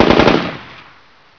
sniper
shoot2.ogg